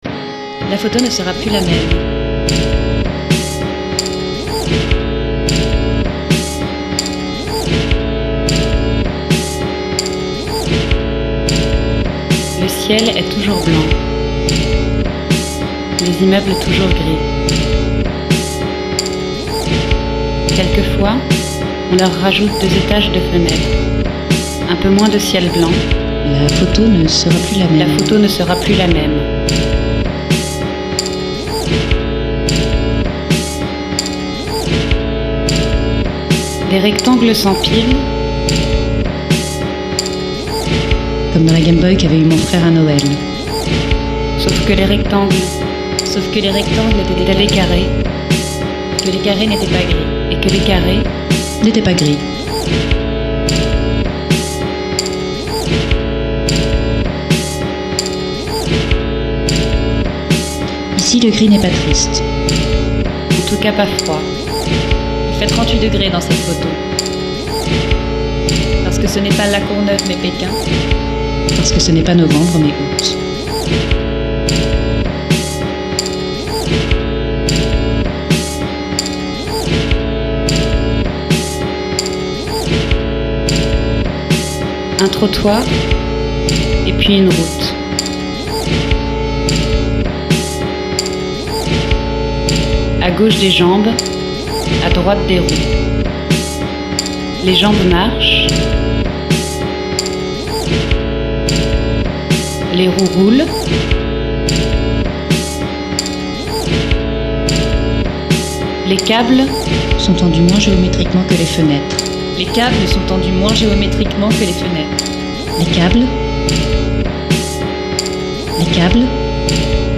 Pièce sonore / image